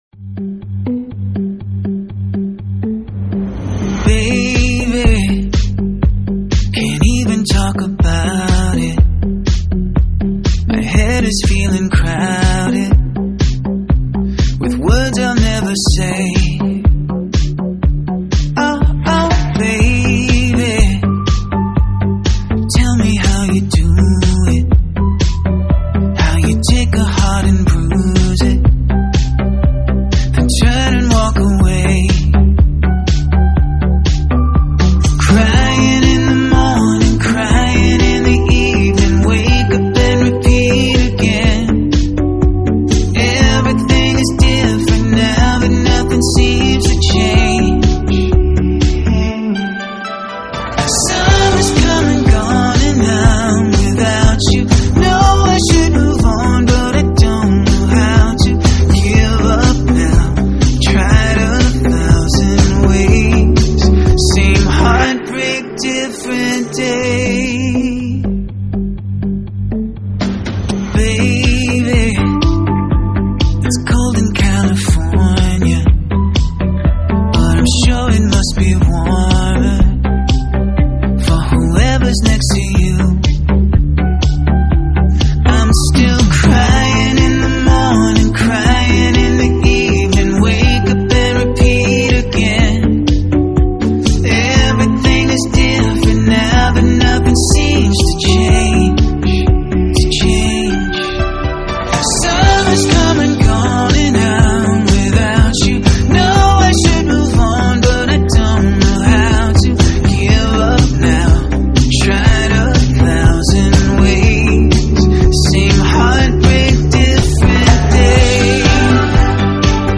Pop Rock, Acoustic